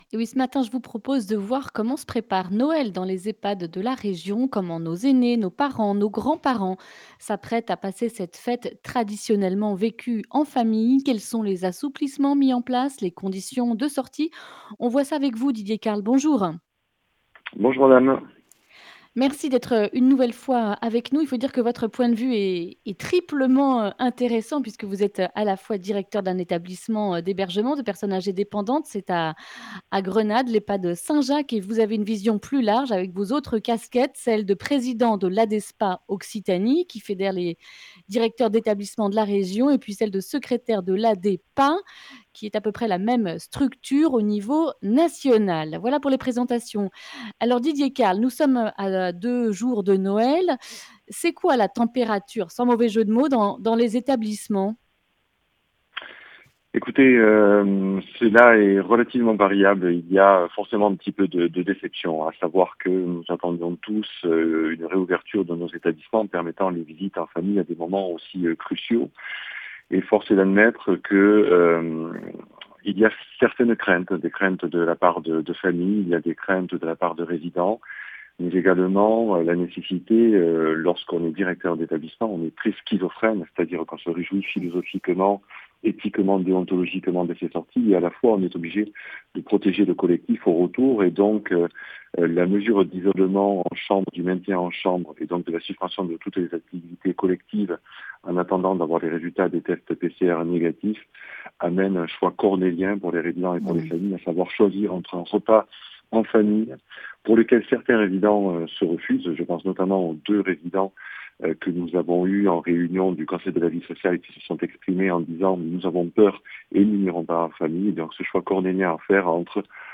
Accueil \ Emissions \ Information \ Régionale \ Le grand entretien \ Comment Noël se prépare dans les Ehpad de la région ?